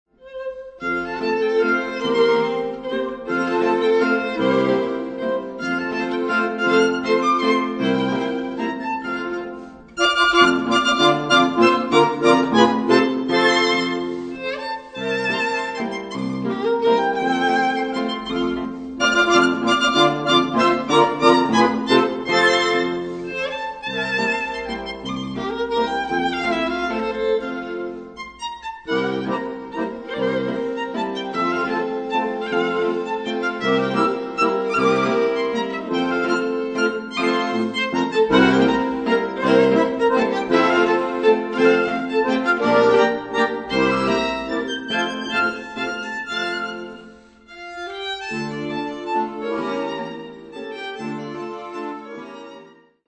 ** Quartett mit Knopfharmonika
Steinerner Saal, Musikverein Wien